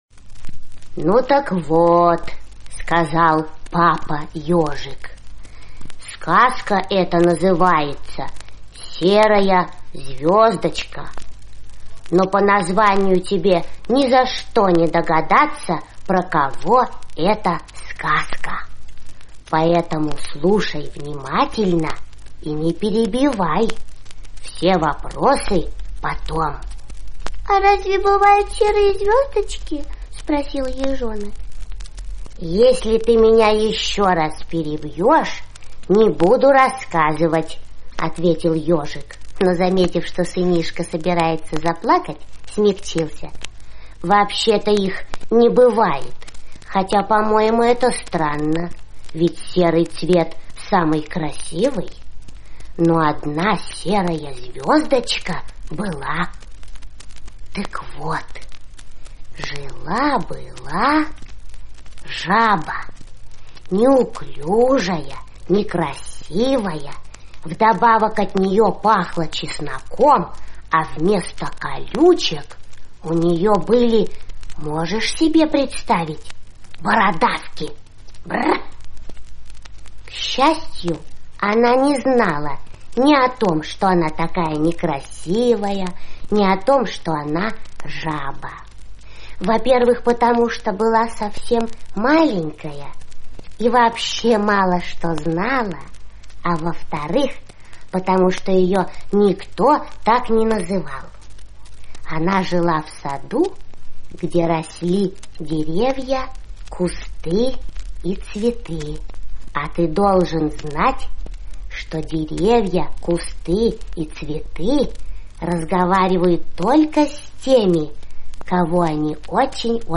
Серая звездочка — слушать аудиосказку Борис Заходер бесплатно онлайн